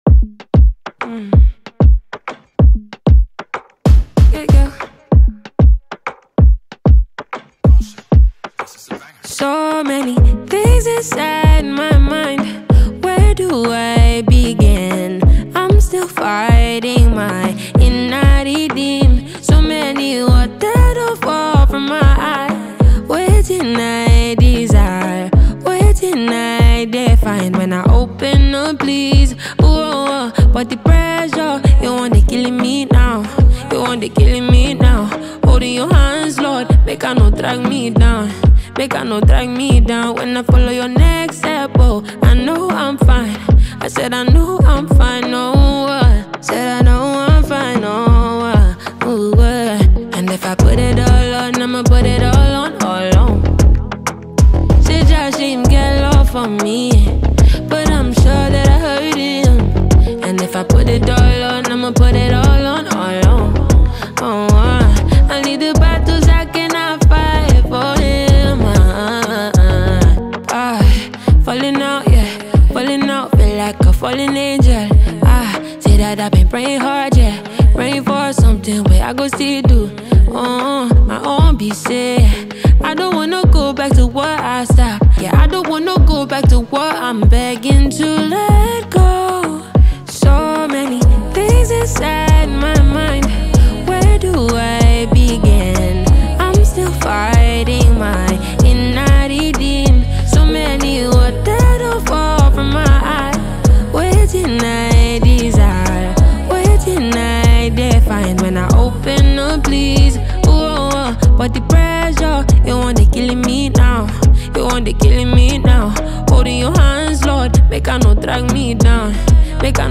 Multi-talented Nigerian Female Songstress and producer